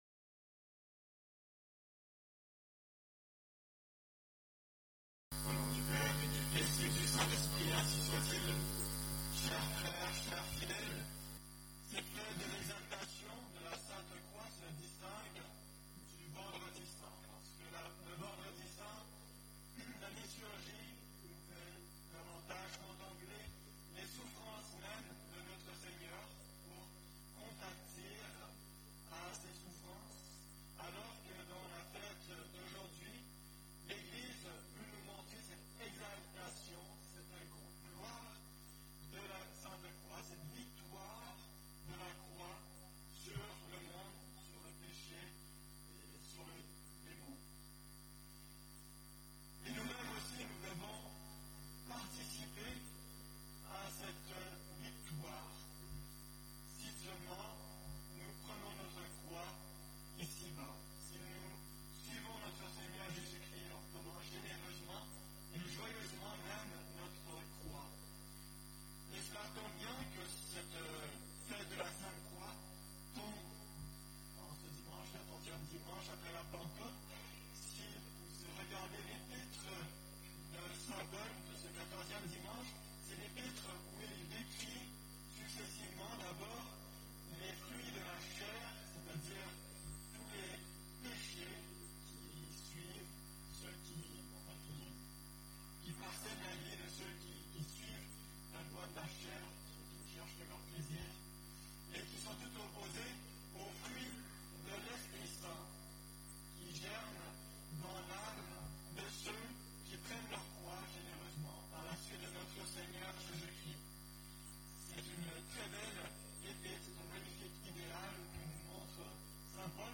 Occasion: Exaltation de la Sainte Croix
Type: Sermons